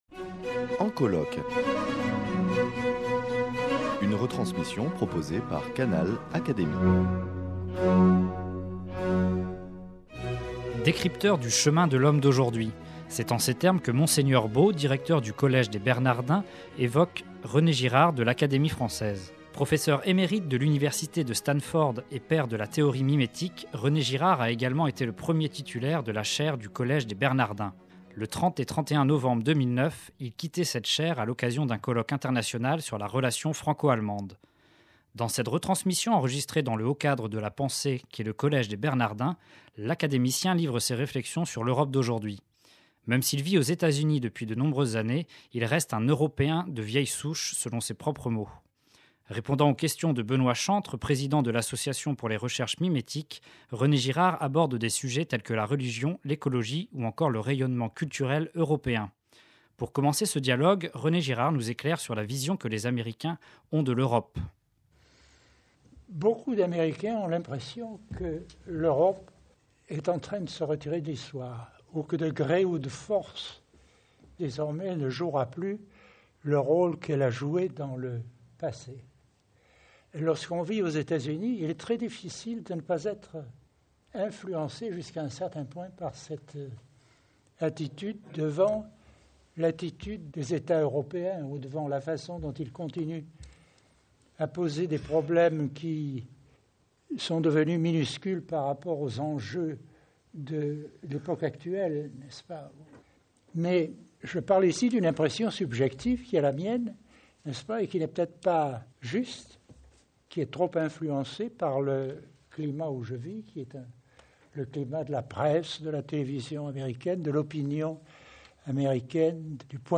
En guise de clôture de ce colloque, le samedi après-midi était consacré à une conférence-dialogue avec René Girard. En évoquant les relations franco-allemandes, René Girard a également parlé de l’Europe et de sa place dans le monde d’aujourd’hui.